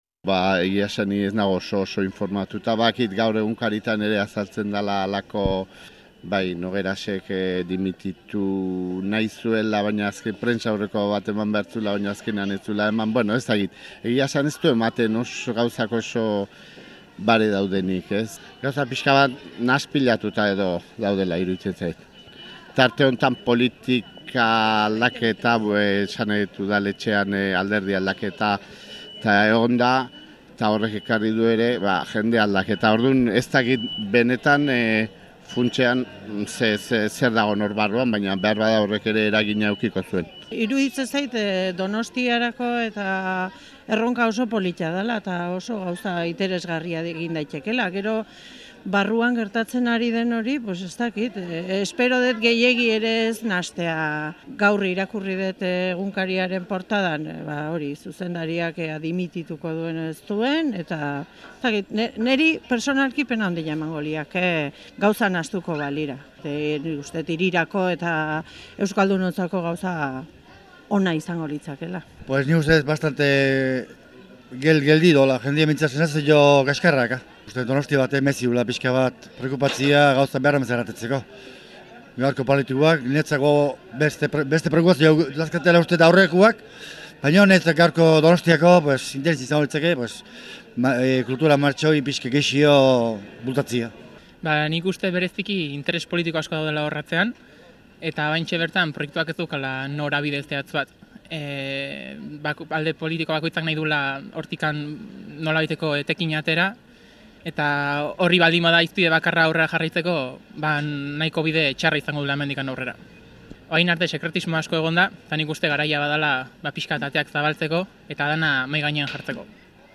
Inkesta